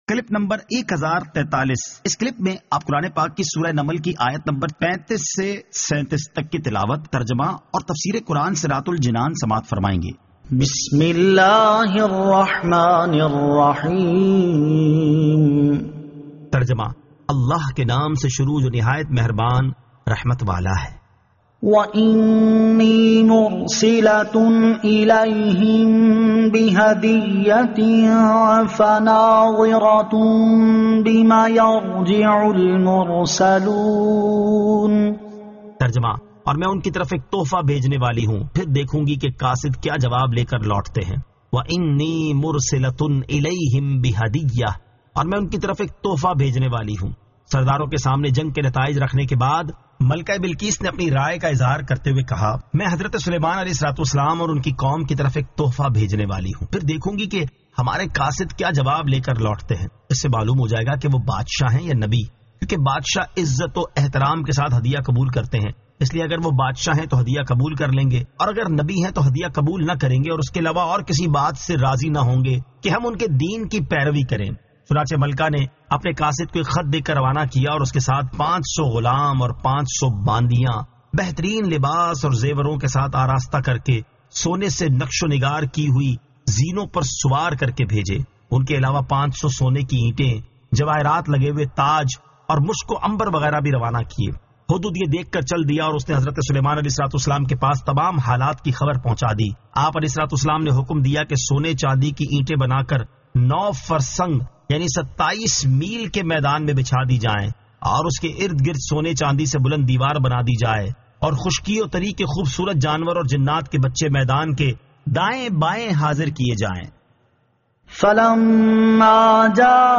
Surah An-Naml 35 To 37 Tilawat , Tarjama , Tafseer